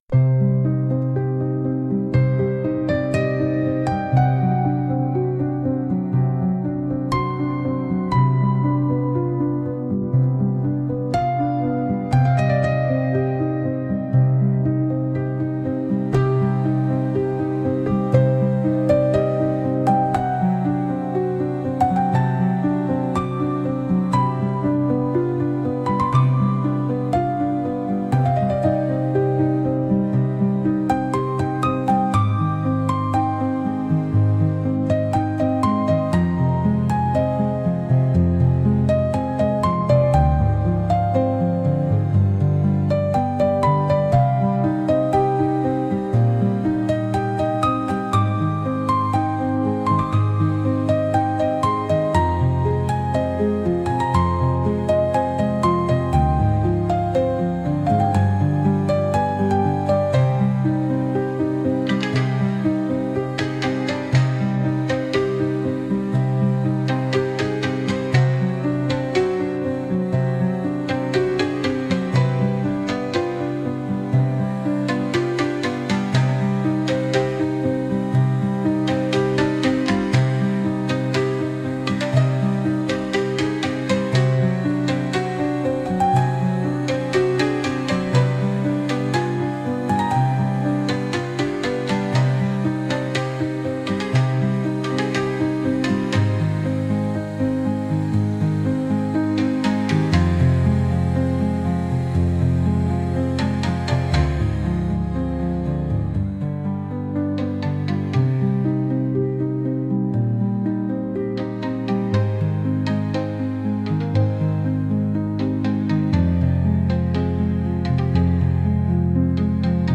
Instrumental- The Weight of Stillness - 2.29 secs